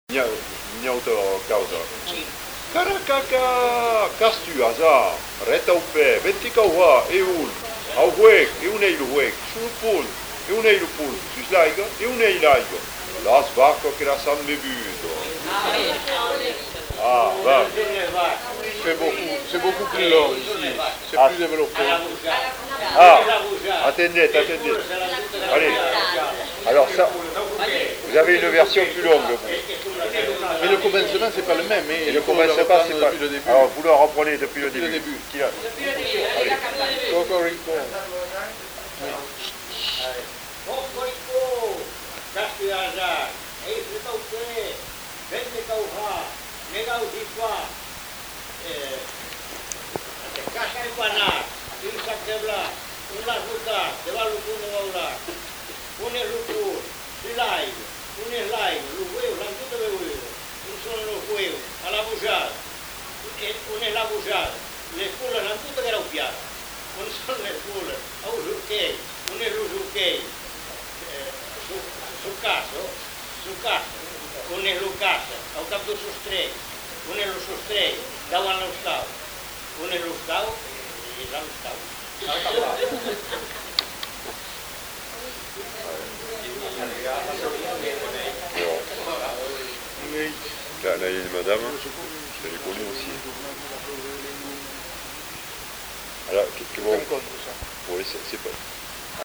Lieu : Bazas
Genre : forme brève
Effectif : 1
Type de voix : voix d'homme
Production du son : récité
Classification : mimologisme
Au début de la séquence l'enquêteur propose une version.